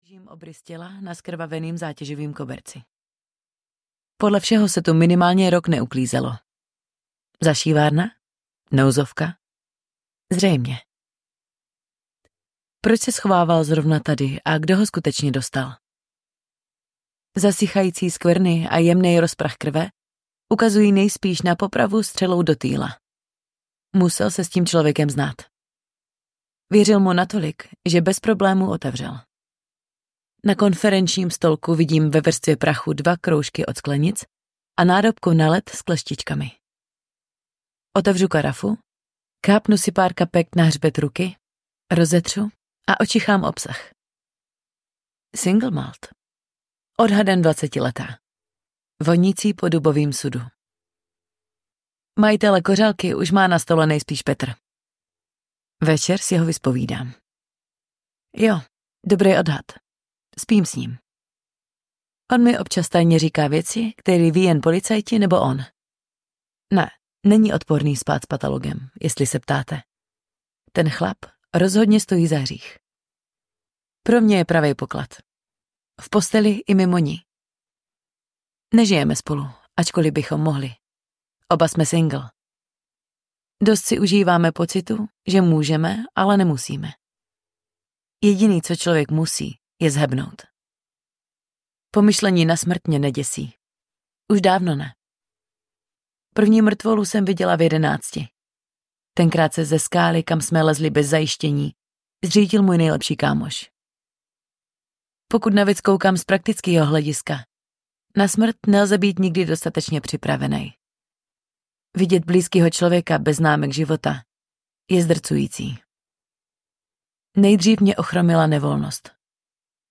Sarkastická svině audiokniha
Ukázka z knihy